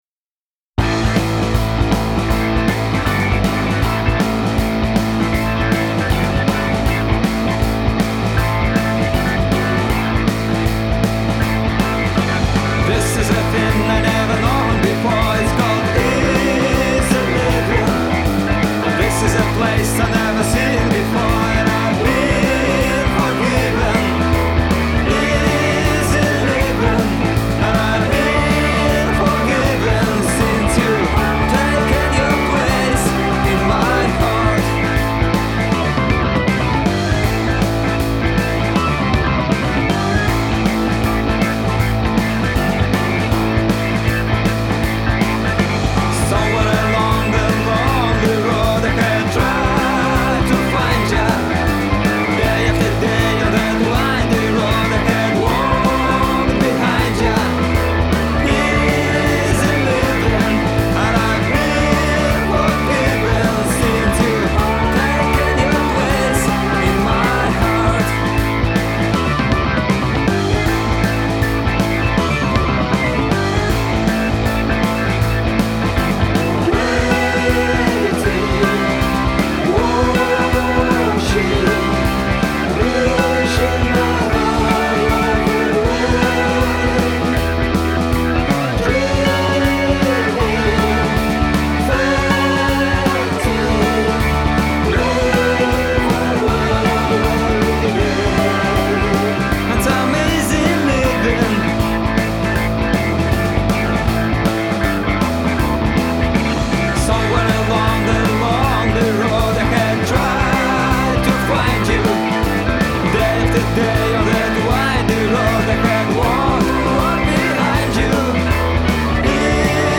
Кавер
vocals, backs, guitar, bass, keys, drums programming